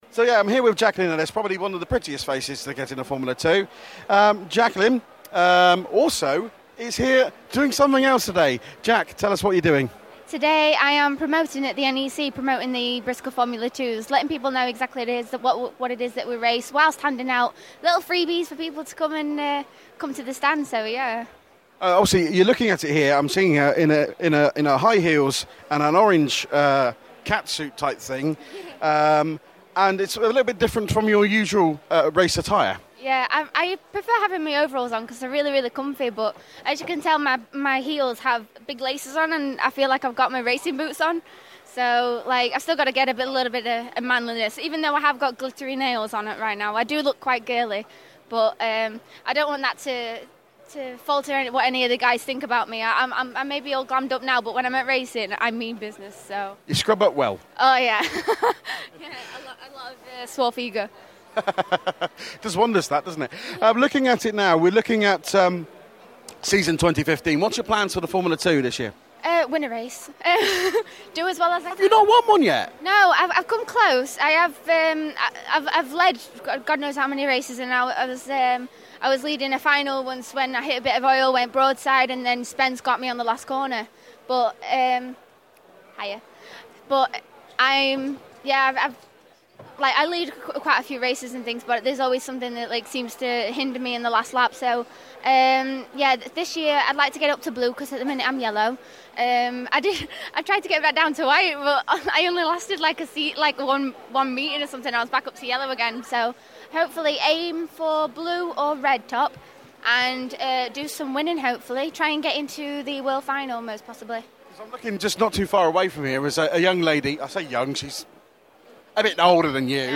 Autosport Show - Interview